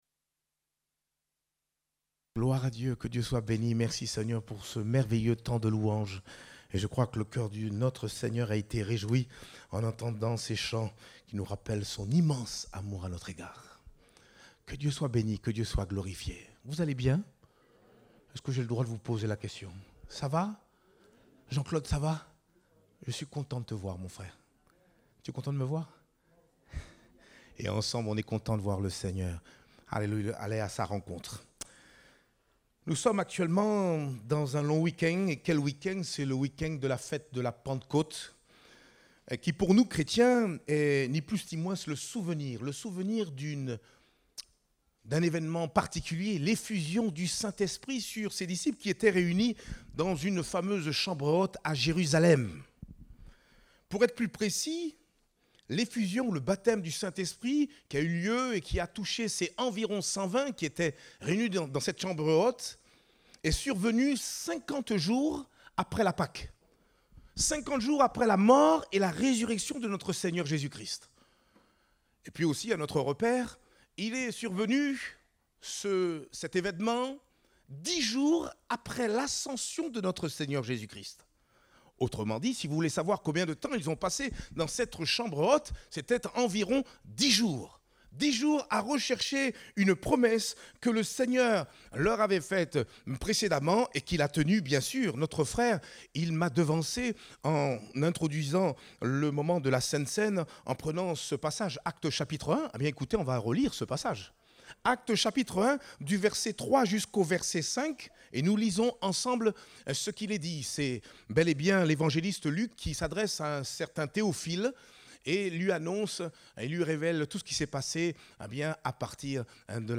Date : 28 mai 2023 (Culte Dominical)